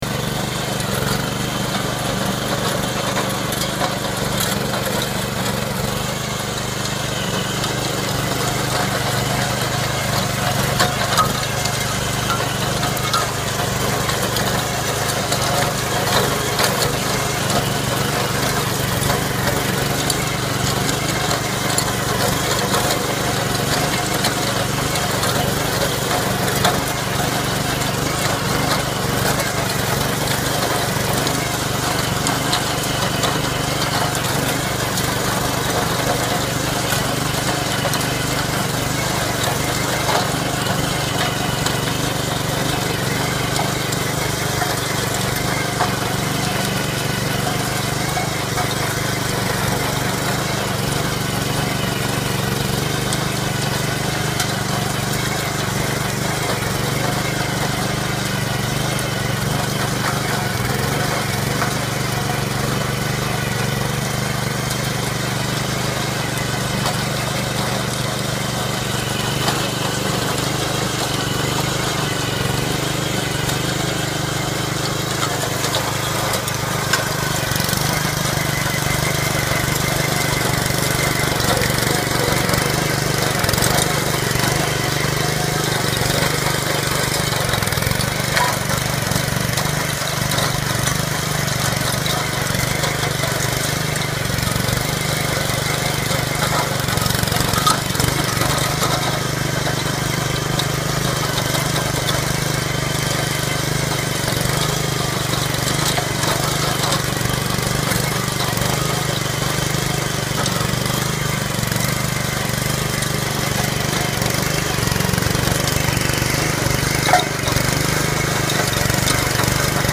Звук мотора мотоблока, фрезы в движении, вспашка твердой земли